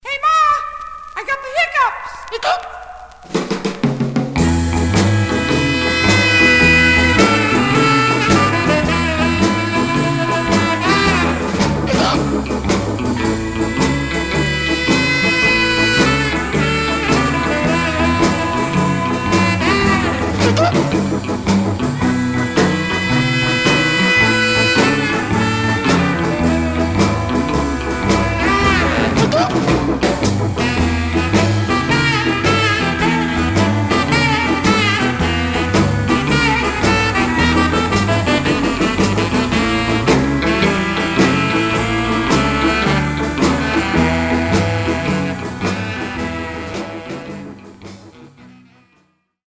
Stereo/mono Mono
R & R Instrumental